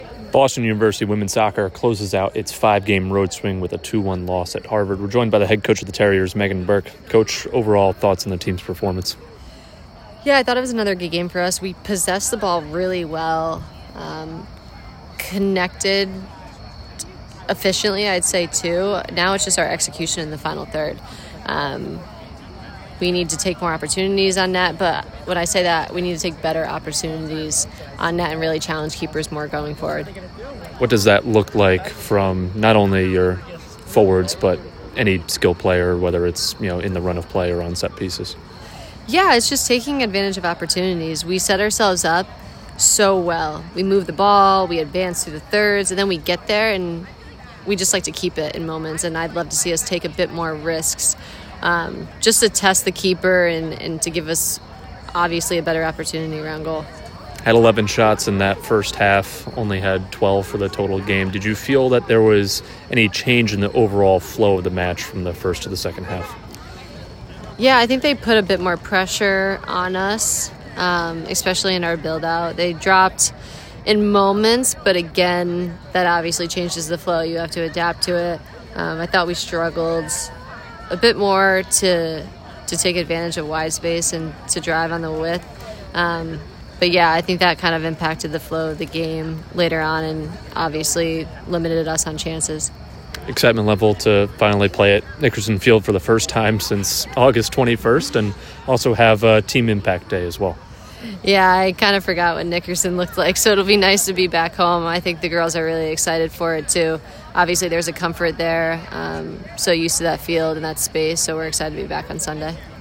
WSOC_Harvard_Postgame.mp3